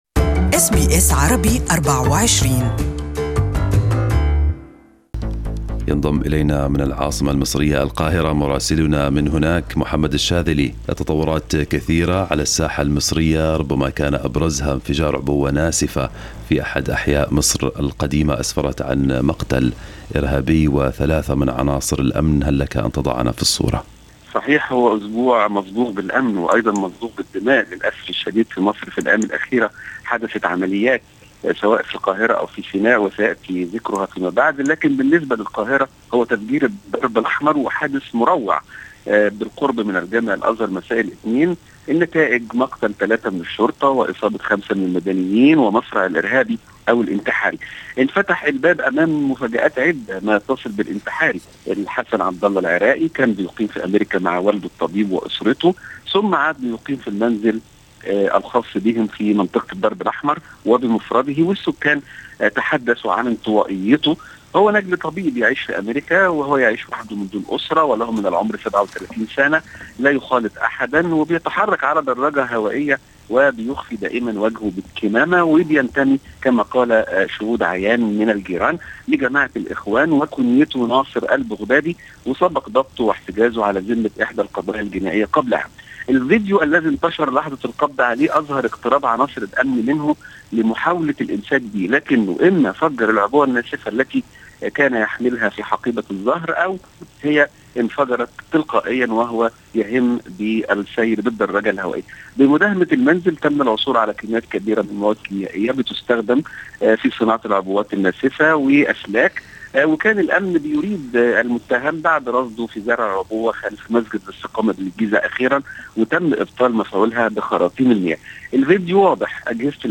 Our correspondent in Egypt has the details